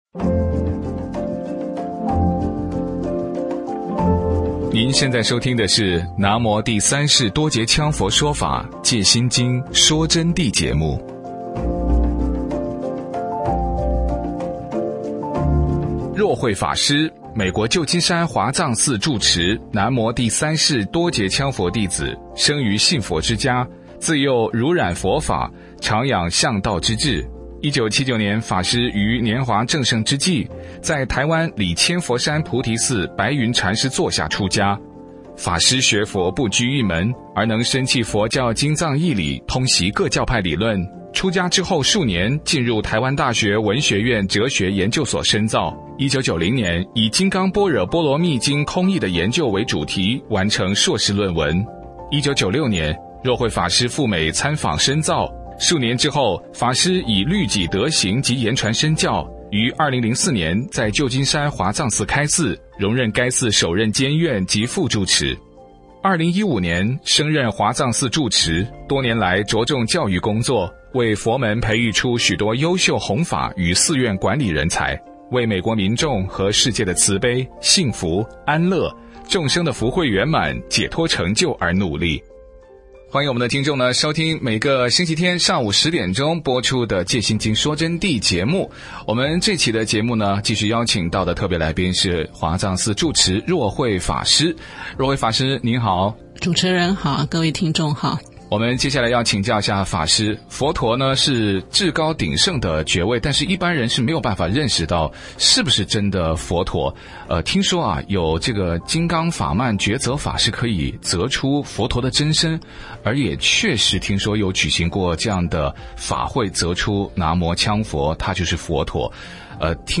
佛弟子访谈（二十一）佛教的金刚法曼择决法择出南无羌佛是佛陀真身的真实情况